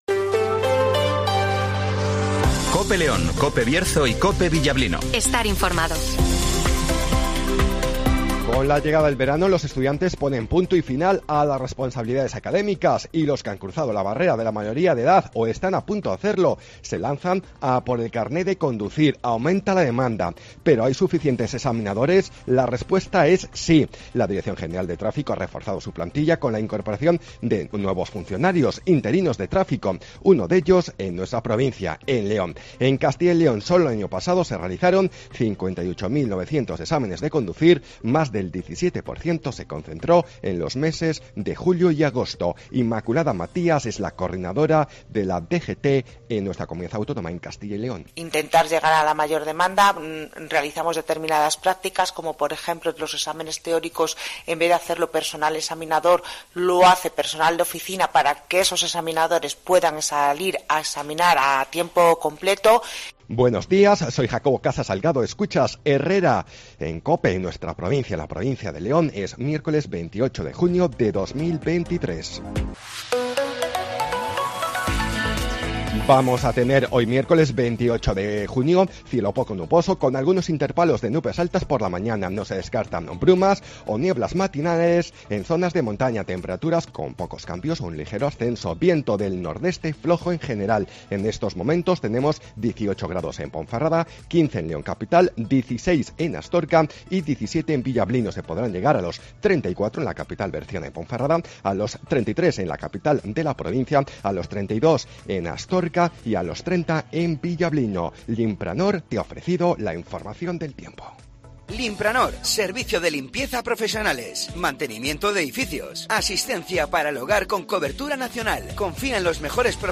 - Informativo Matinal 08:25 h